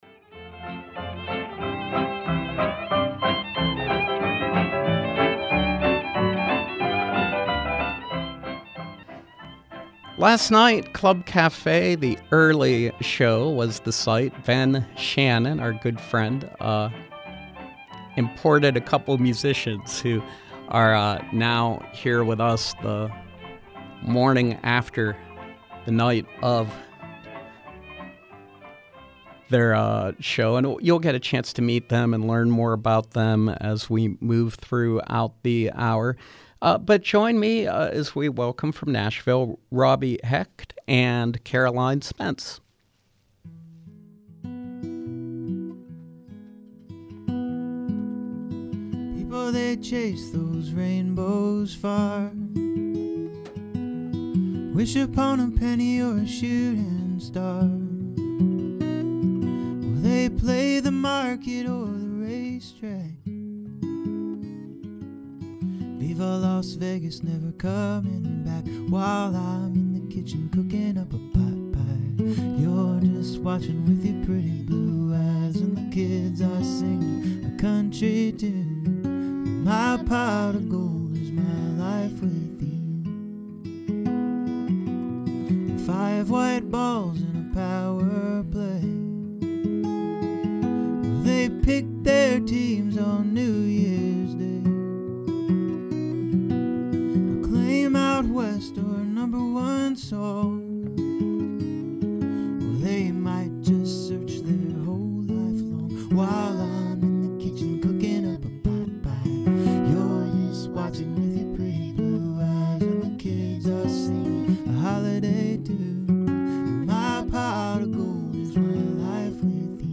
Nashville-based duo